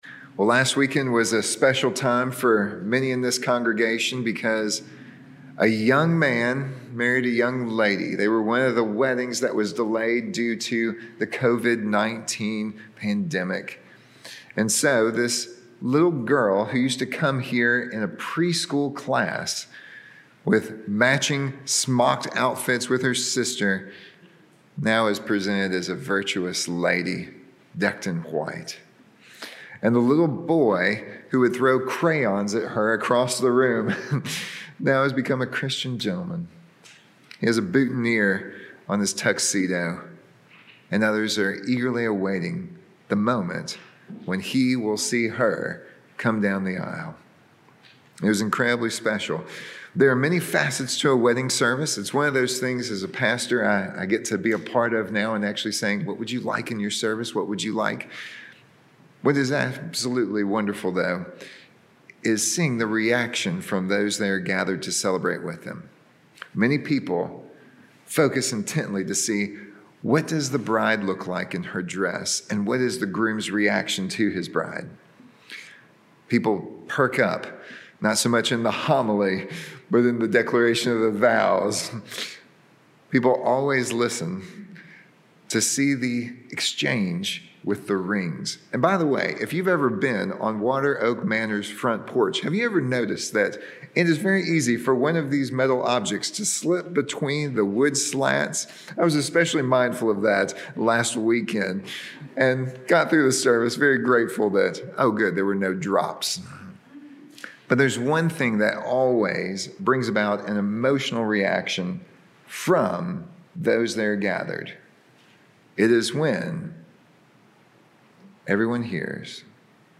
Sermons | Covenant Presbyterian Church